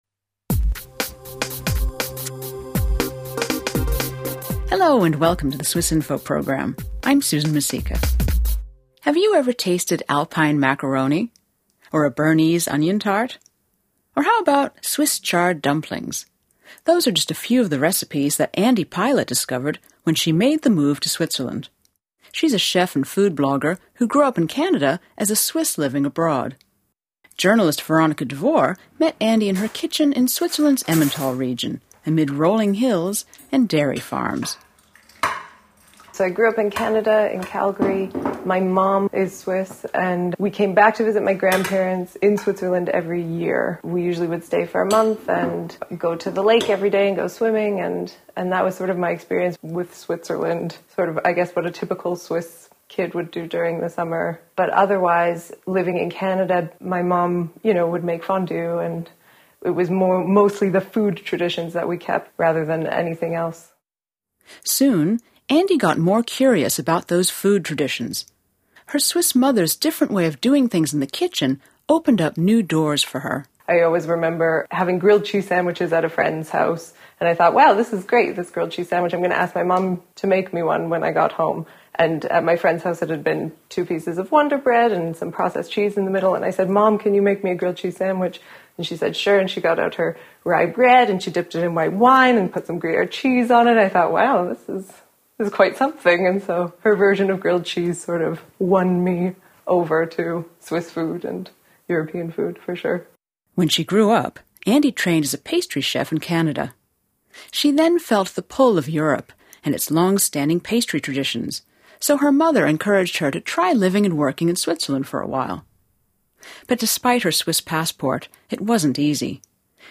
A Swiss-Canadian chef and food blogger explains how she found her place in Switzerland through recipes from her mother’s homeland.